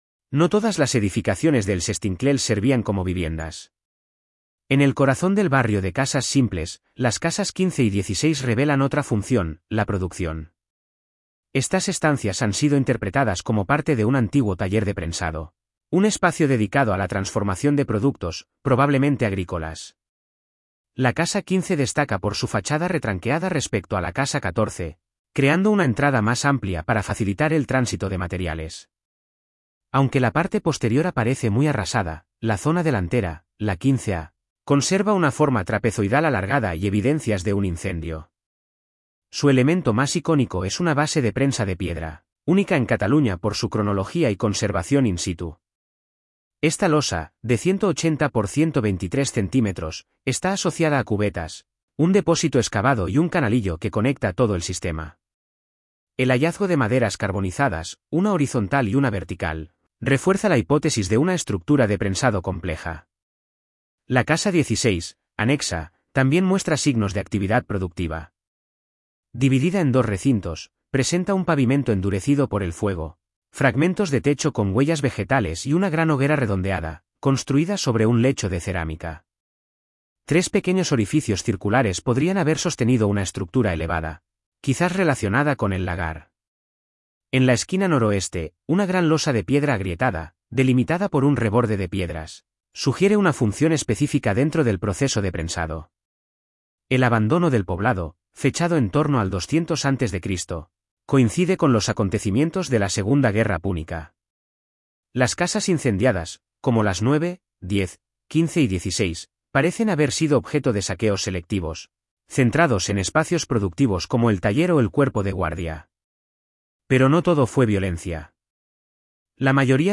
Audioguía Taller de prensado